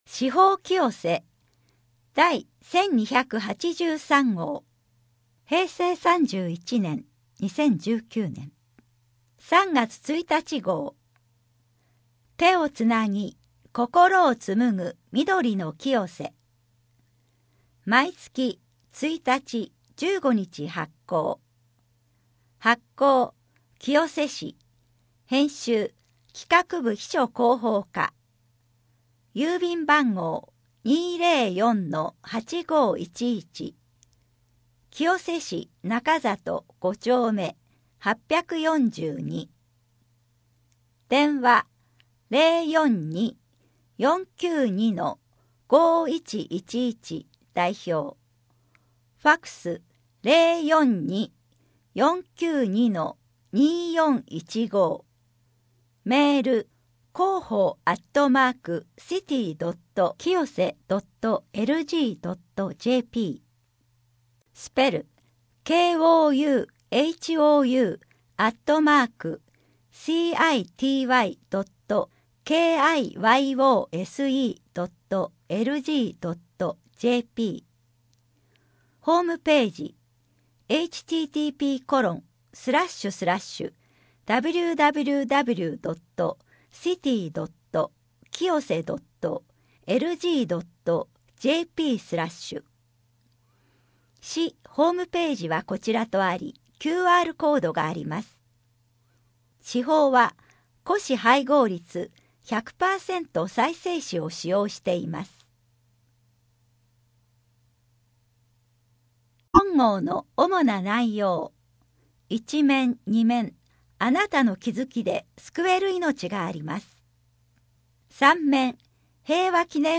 声の広報 声の広報は清瀬市公共刊行物音訳機関が制作しています。